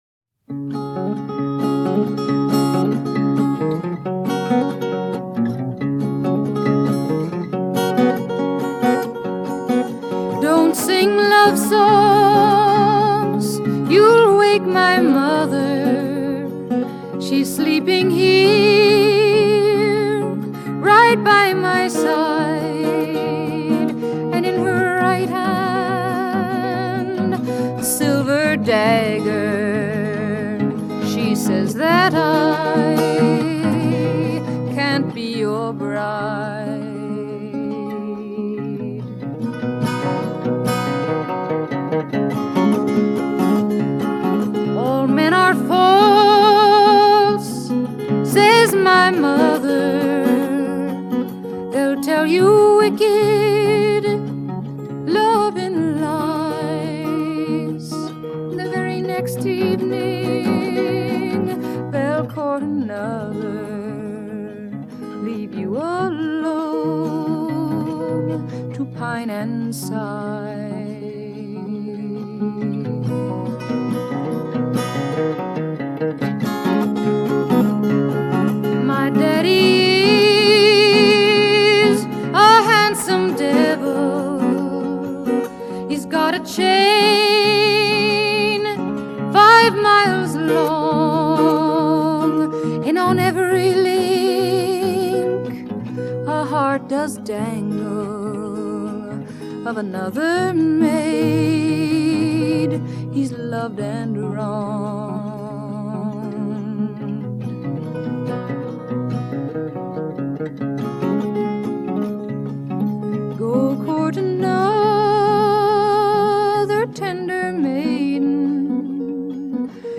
Folk / Acoustic / Protest Song